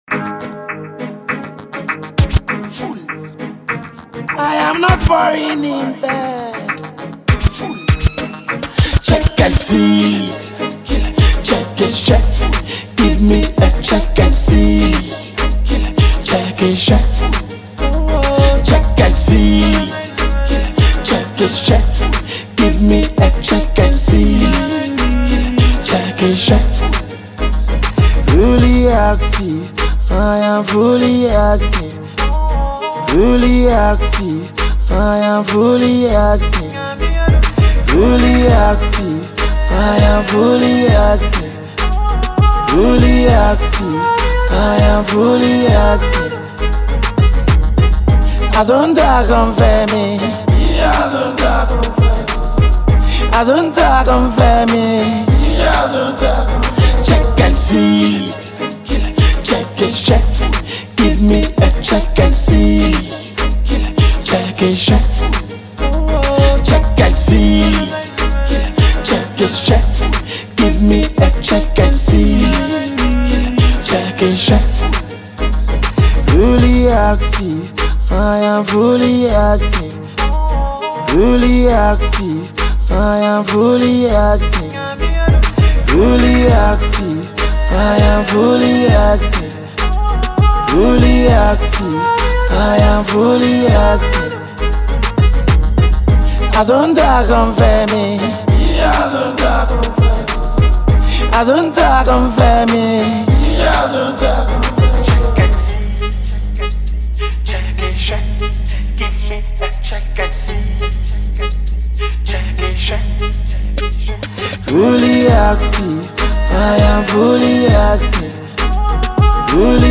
energetic tune